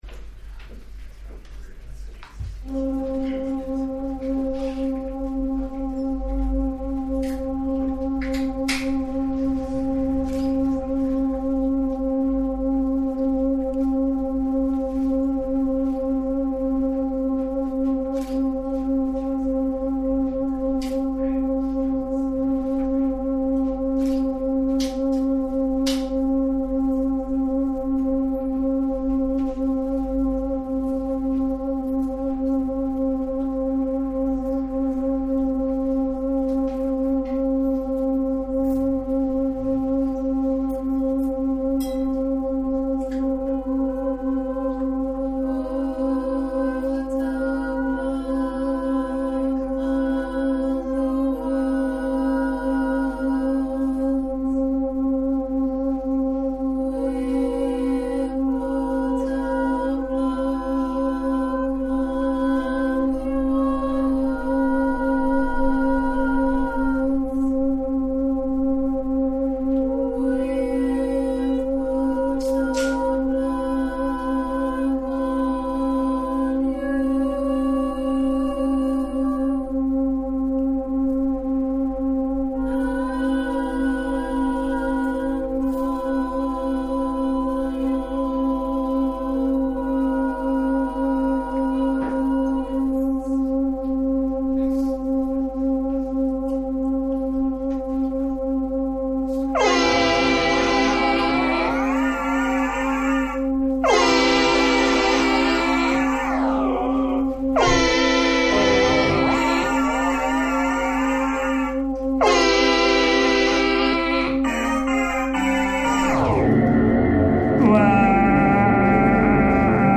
GOB brings together a number of artists working with voice for two evenings of live performances. These artists will present works that draw from areas such as sound poetry, performative and theatrical actions, text/sound composition, and experimental music. These works will examine the intrinsic relationship voice has with meaning, and clear communicative speech, considering ways in which this relationship can be deformed, mutated, and rendered unstable through various modes of performance.
Gob-Choir.mp3